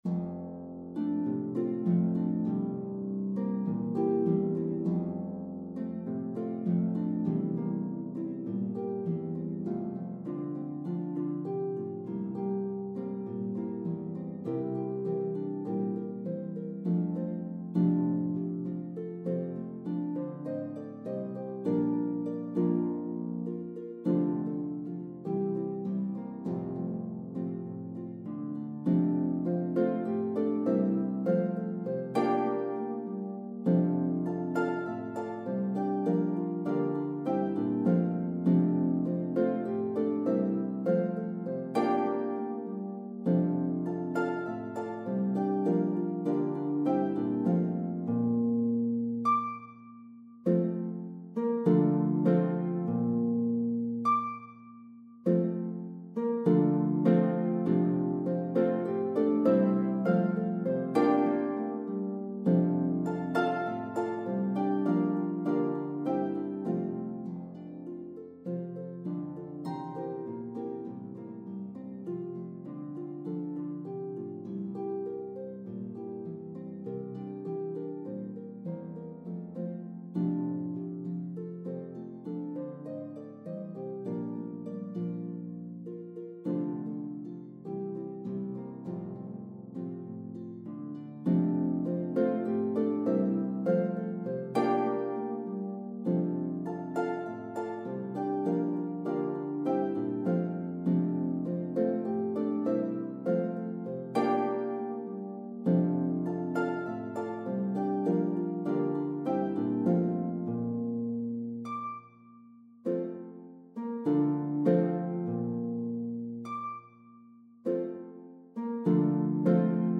Glissandos can be played diatonically on Lever Harps.
West Indian Carol